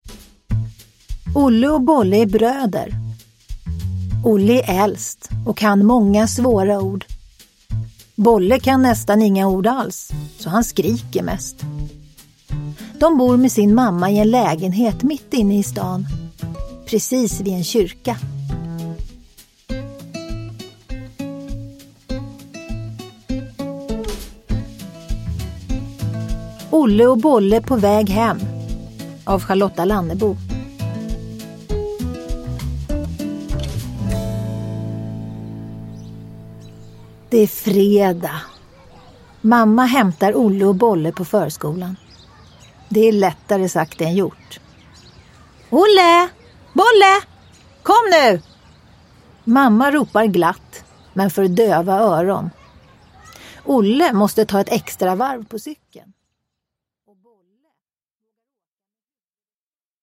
Olle och Bolle på väg hem – Ljudbok – Laddas ner